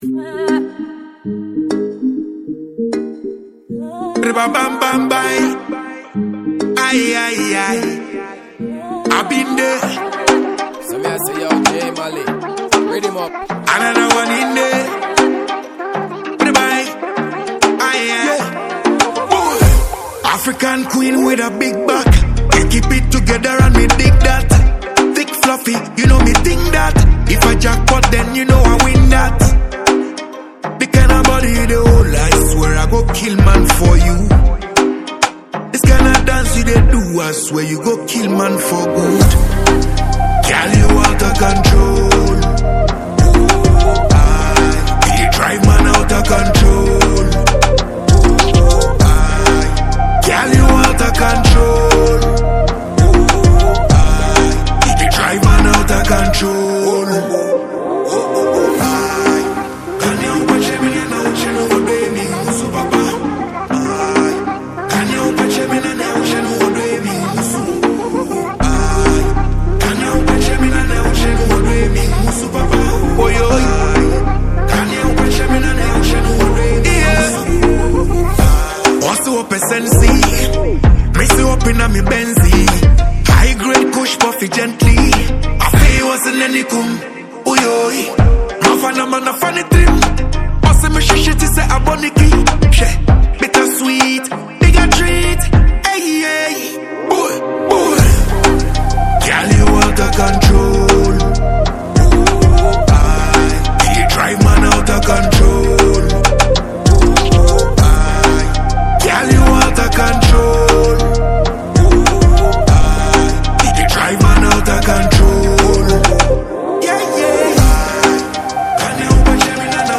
There’s a playful but bold tone in the song.
• Genre: Dancehall / Afrobeat